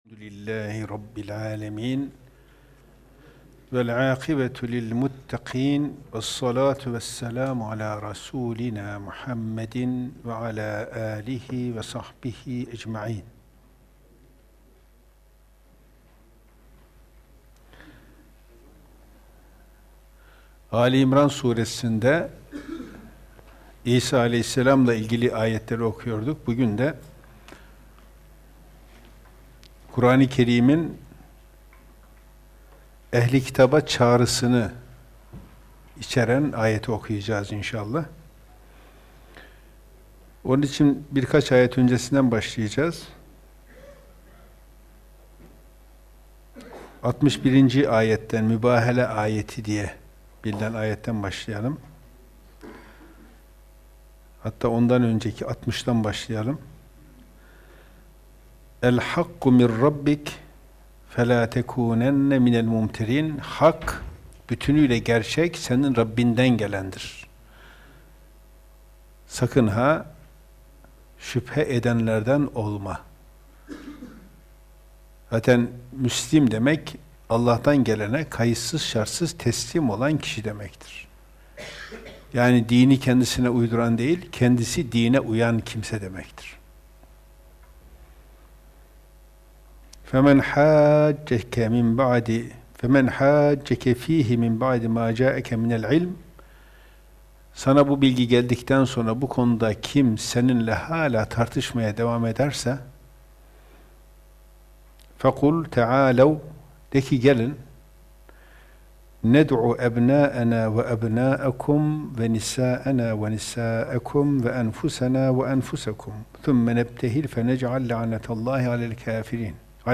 Ayet – Dinler Arası Diyalog İhaneti – Kuran Dersi